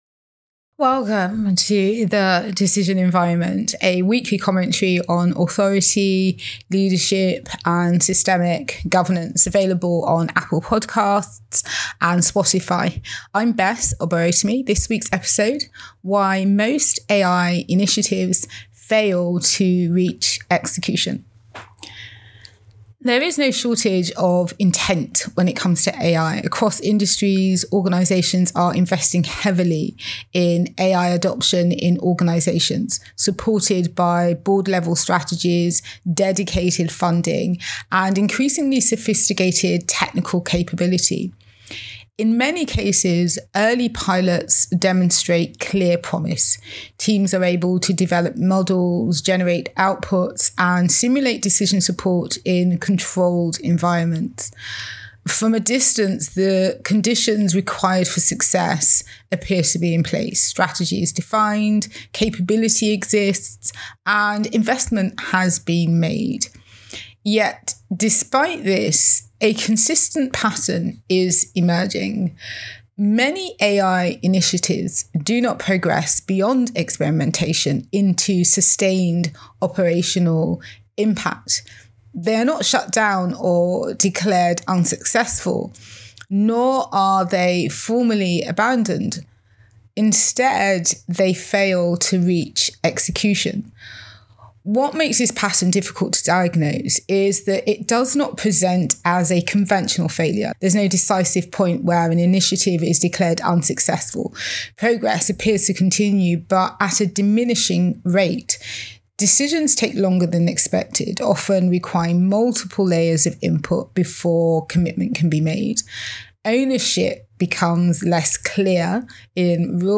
Audio Commentary Today I want to talk about why most AI initiatives fail to reach execution.
why-most-ai-initiatives-fail-to-reach-execution-audio-commentary.mp3